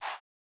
Vox (R.I.P. SCREW)(1).wav